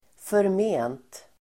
Uttal: [förm'e:nt]